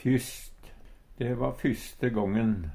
DIALEKTORD PÅ NORMERT NORSK fysst fyrst Ubunde han-/hokj.